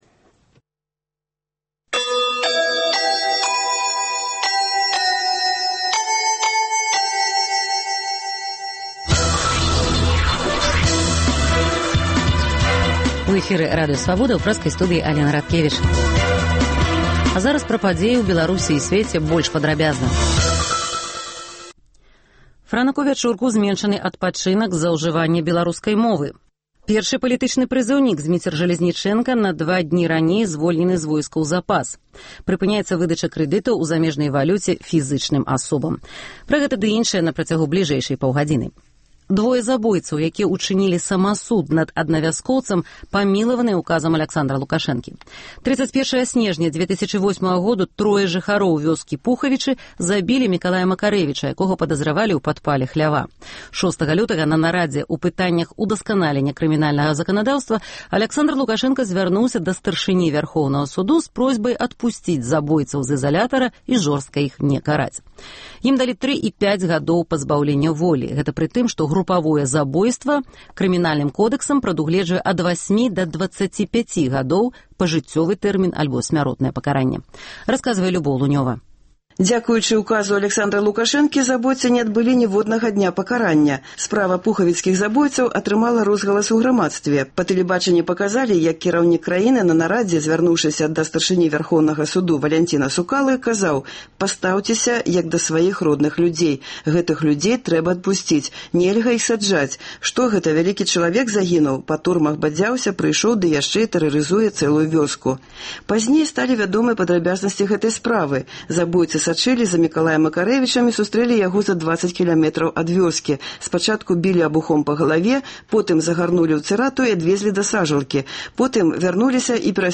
Паведамленьні нашых карэспандэнтаў, званкі слухачоў, апытаньні ў гарадах і мястэчках Беларусі